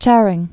(chărĭng)